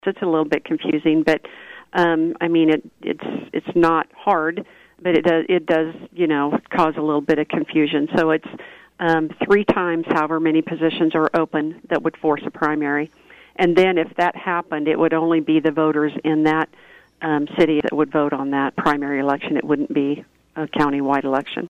With the 2023 election cycle now underway, Lyon County Clerk Tammy Vopat recently joined KVOE’s Morning Show to remind voters of some important upcoming dates.